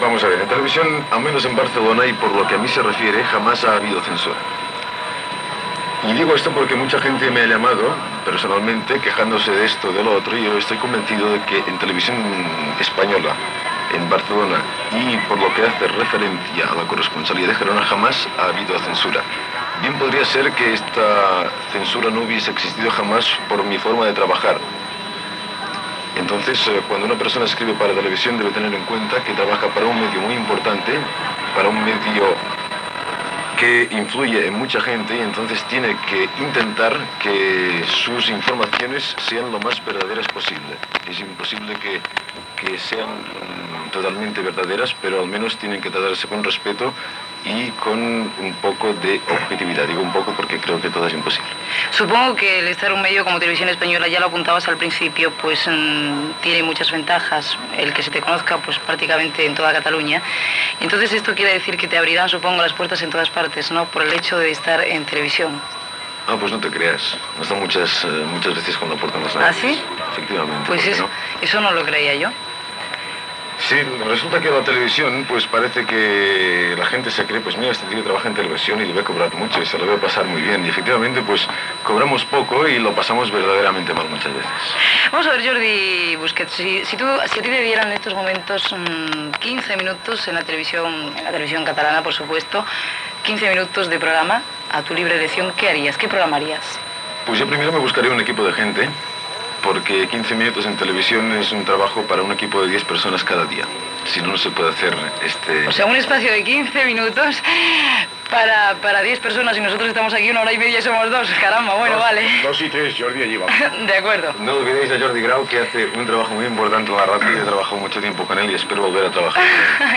Concurs amb trucades telefòniques, tema musical, demanda de cartes, comiat del programa i publicitat